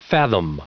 Prononciation du mot fathom en anglais (fichier audio)
Prononciation du mot : fathom